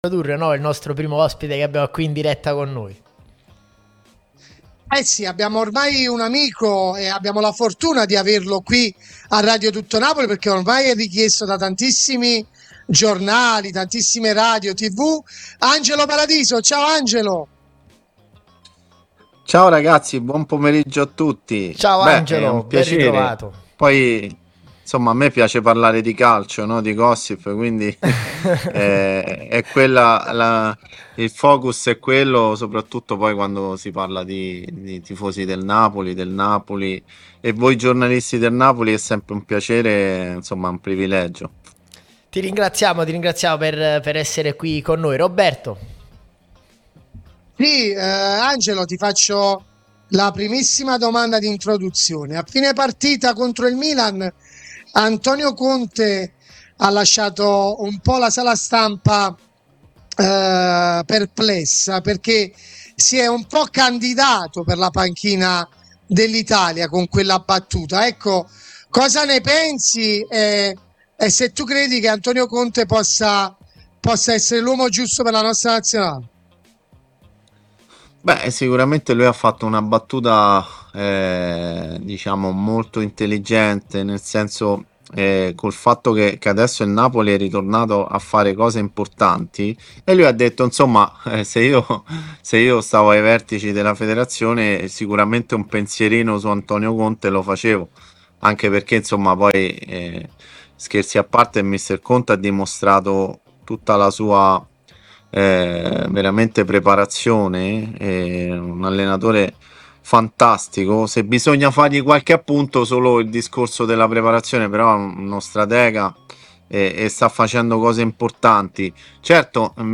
è intervenuto sulla nostra Radio Tutto Napoli